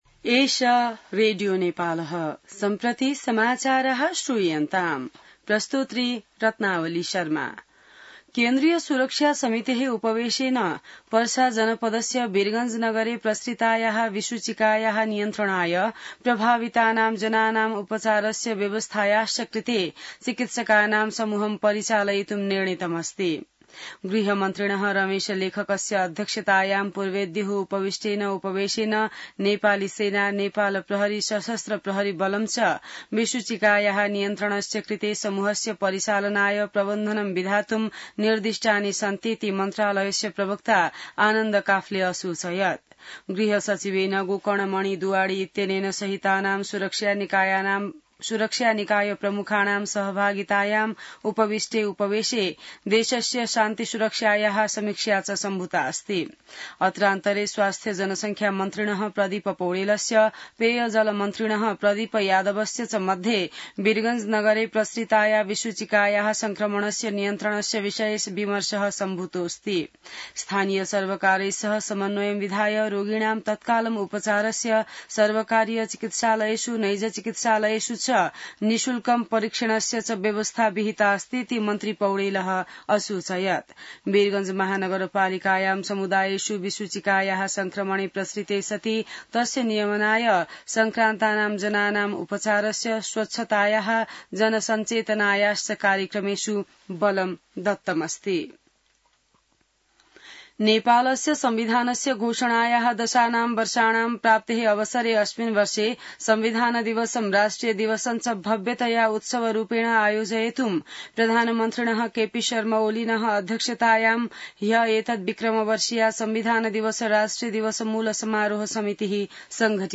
संस्कृत समाचार : ९ भदौ , २०८२